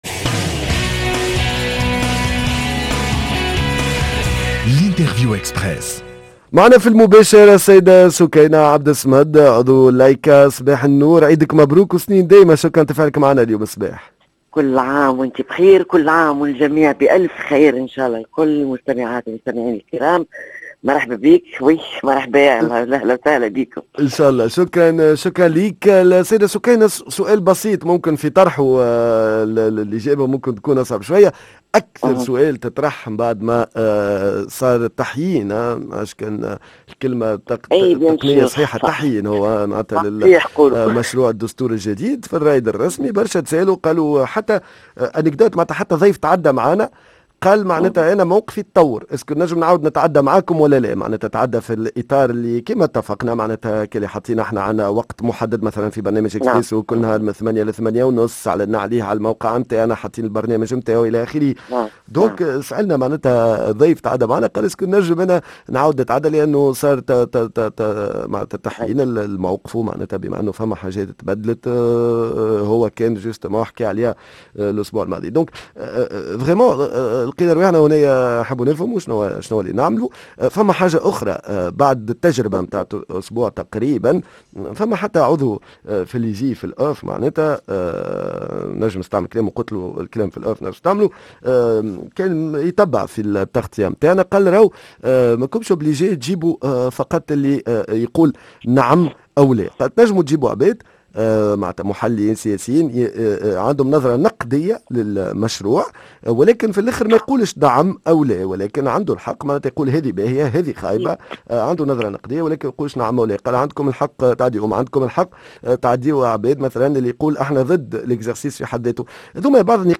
هل ان التغطية الإعلامية للاستفتاء تتغير بعد التعديلات الطارئة على مشروع الدستور ، معانا عبر الهاتف عضو الهيئة العليا المستقلة للاتصال السمعي البصري سكينة عبد الصمد